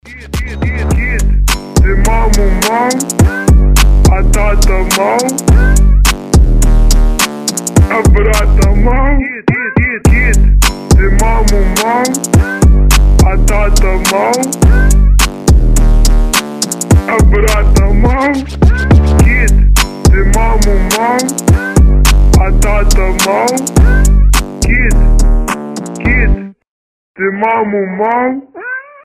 веселые
мощные басы
смешные
мяу
пародии